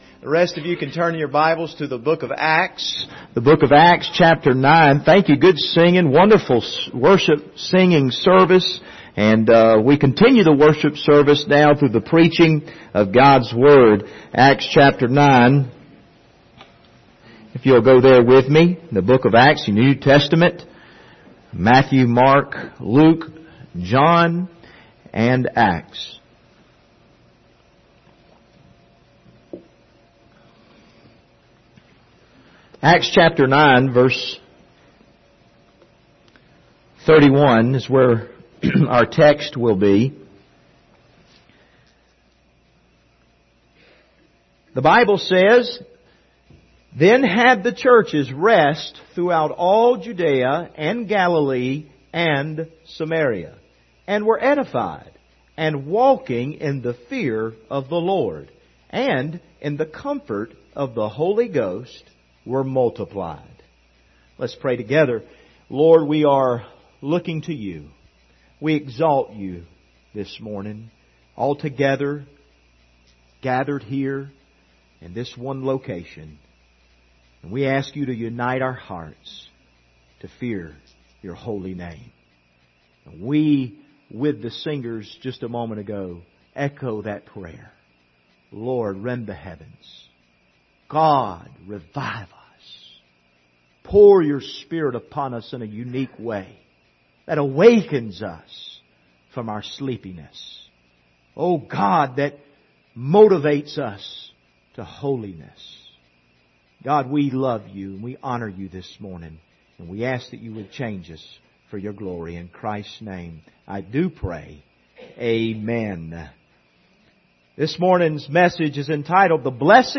Passage: Acts 9:31 Service Type: Sunday Morning « We Have Found Him